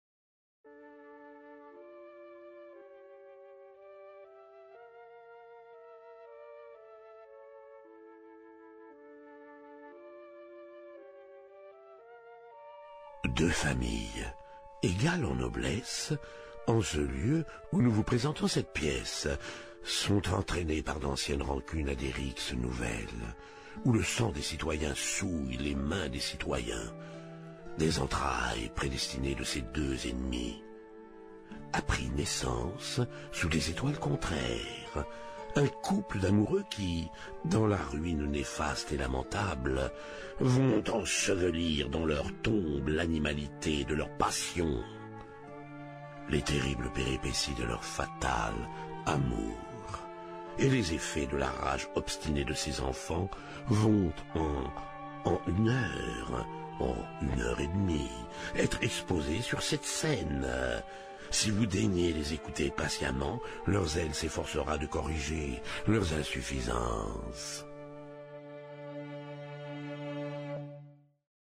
La Voix  : Jean-Claude DREYFUS